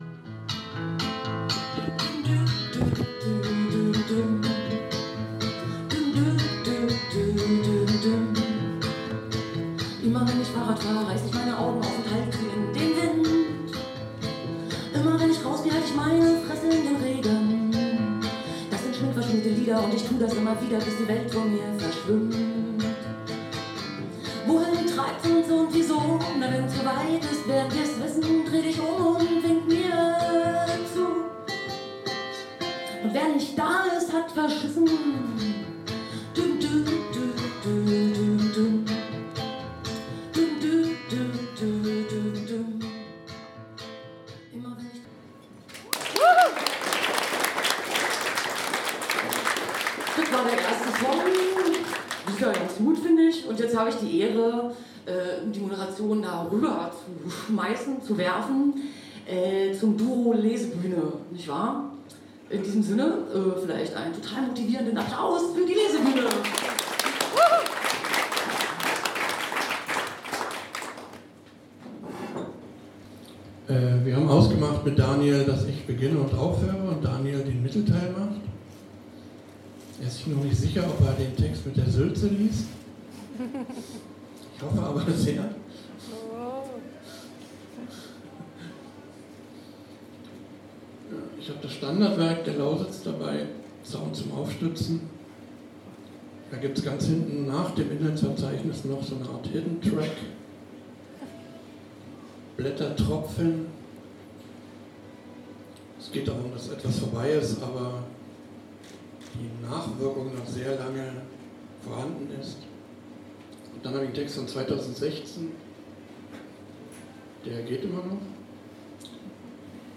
Lesung und Gespräch